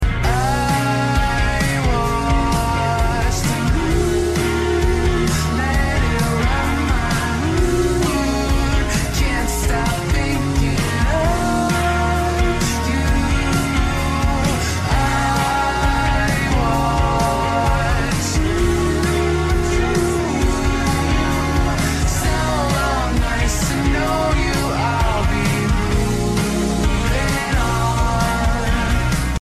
slowed and reverbed